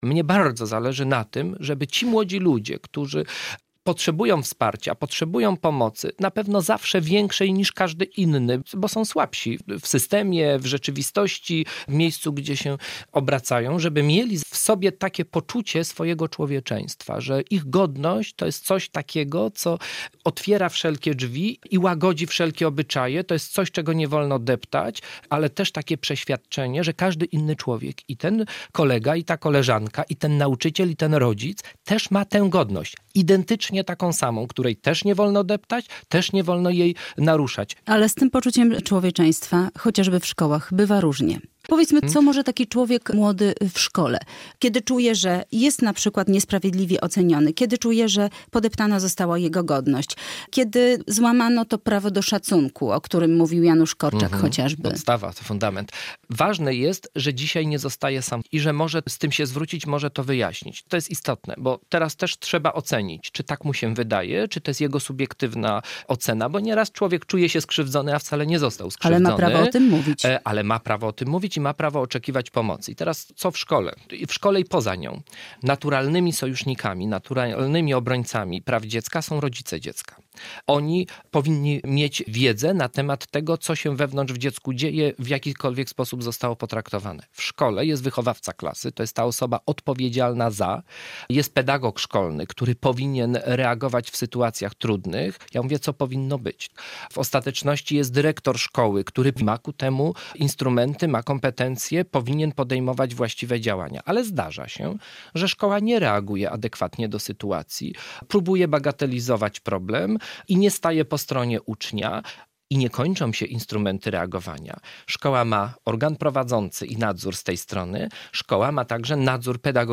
Radio Białystok | Gość | Marek Michalak - Rzecznik Praw Dziecka